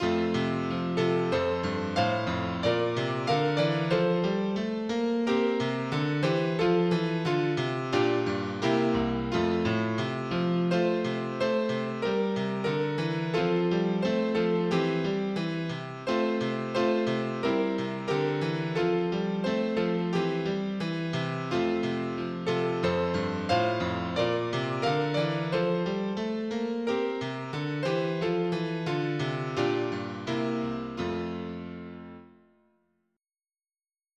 folk
MIDI Music File